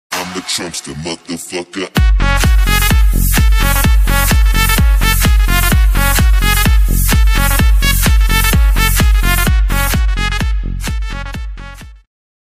patlamalk-telefon-zil-sesi-vol-1.mp3